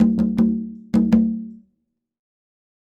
Conga Fill 02.wav